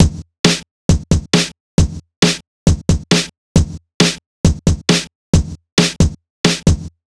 Jfx Bd _ Snr.wav